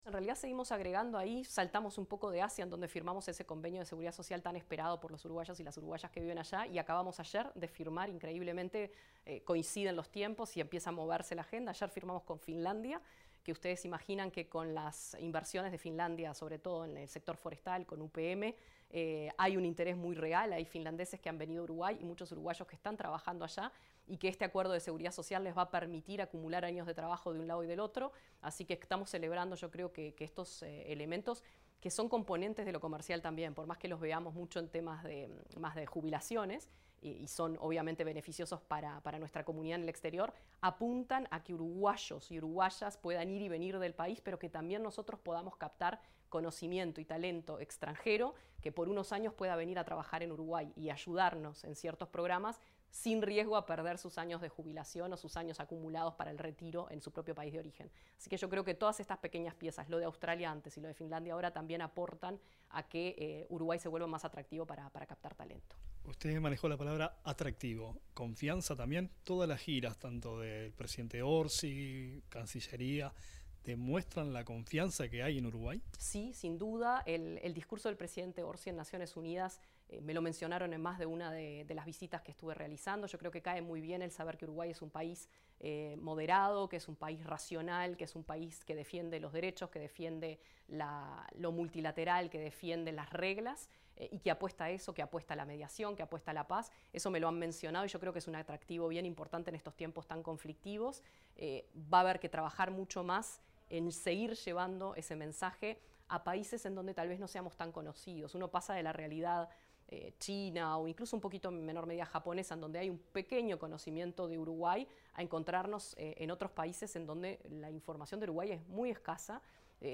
Declaraciones de la ministra interina de Relaciones Exteriores, Valeria Csukasi